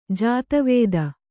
♪ jāta vēda